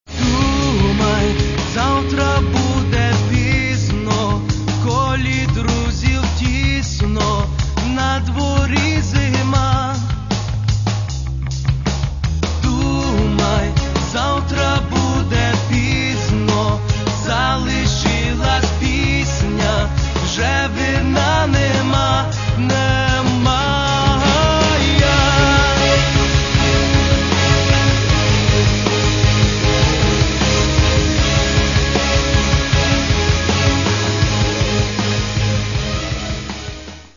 Каталог -> Рок и альтернатива -> Энергичный рок